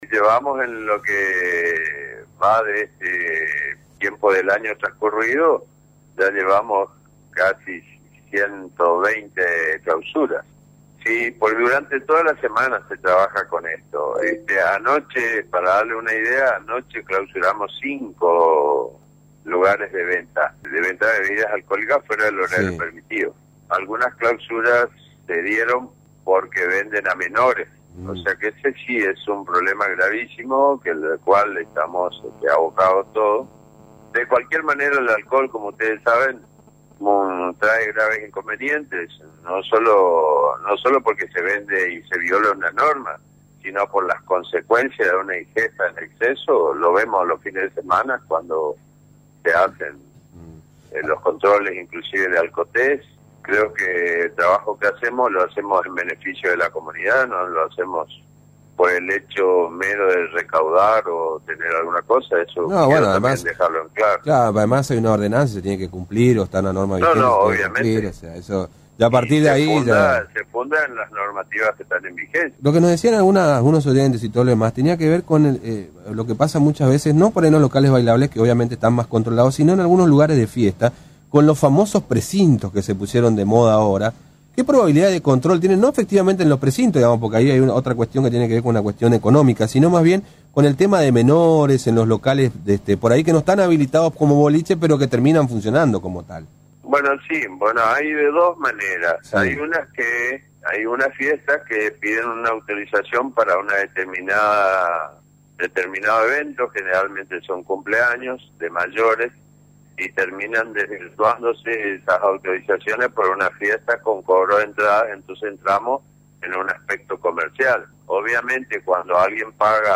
El programa «Algo Está Pasando» (emitido de lunes a viernes de 8 a 12 horas, por QTH FM 101.9 y en simultaneo por VLU 88.5) se refirió a los numerosos comercios clausurados en lo que va del año. Otro tema emergente fue la problemática de las fiestas «encubiertas», se trata de una cuestión muy presente durante las noches formoseñas.